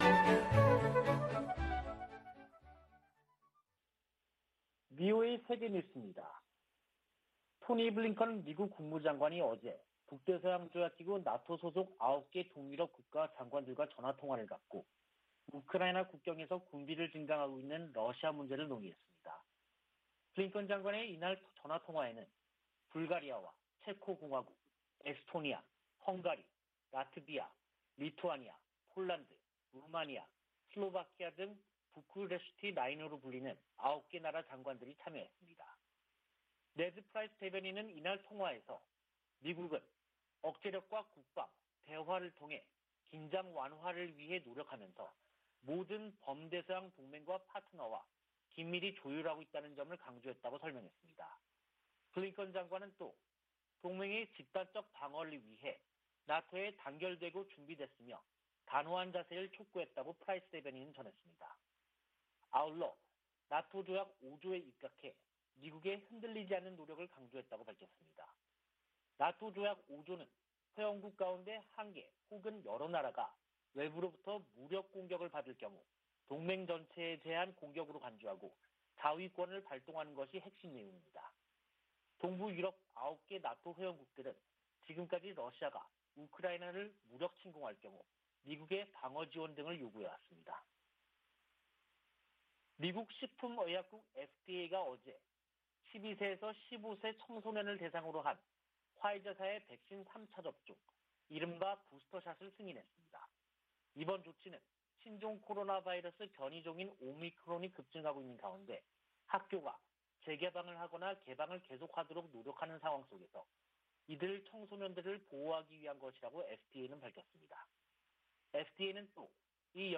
VOA 한국어 간판 뉴스 프로그램 '뉴스 투데이', 2022년 1월 4일 2부 방송입니다. 미 국무부 동아시아태평양국이 조 바이든 행정부 들어 대북 외교에서 역할을 복원 중이라는 감사보고서가 나왔습니다. 미국 정부가 북한에 대화 복귀를 거듭 촉구했습니다. 주요 핵무기 보유국들이 핵무기 사용에 반대하고, 핵확산금지조약(NPT)의 의무를 강조하는 공동성명을 발표했습니다.